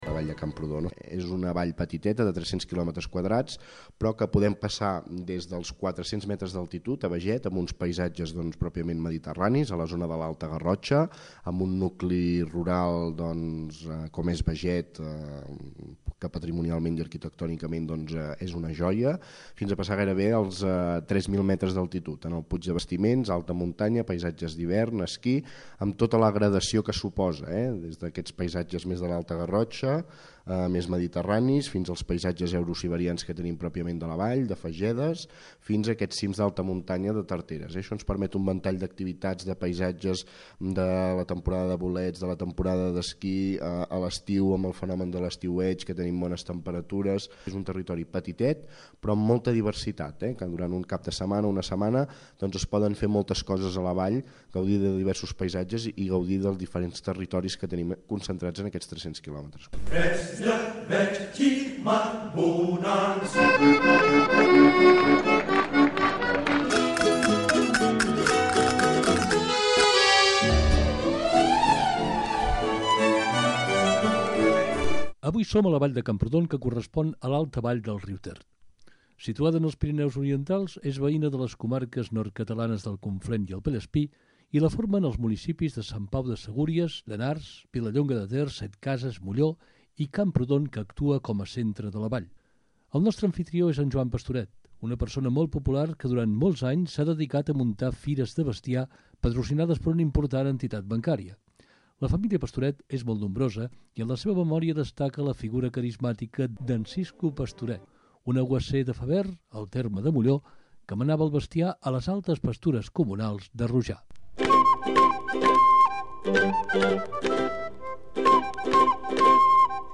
esmorzar ramader i tertúlia a Molló
Divulgació